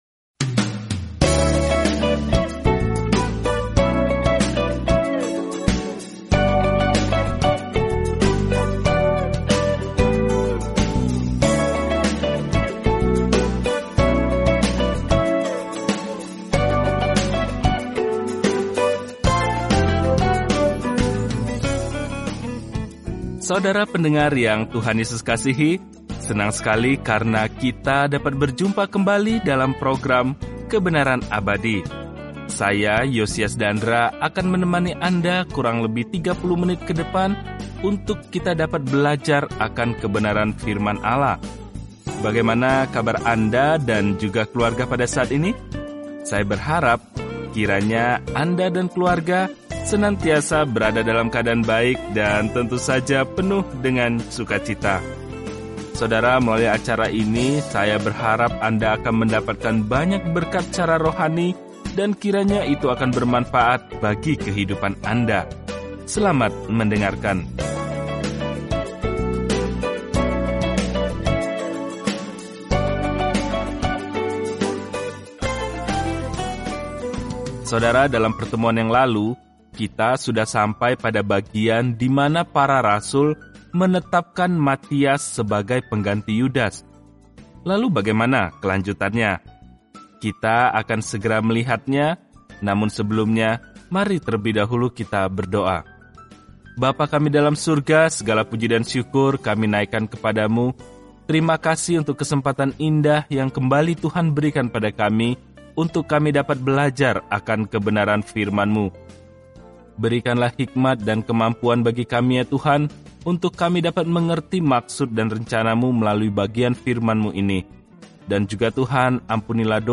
Jelajahi Kisah Para Rasul setiap hari sambil mendengarkan studi audio dan membaca ayat-ayat tertentu dari firman Tuhan.